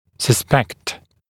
[sə’spekt][сэ’спэкт]подозревать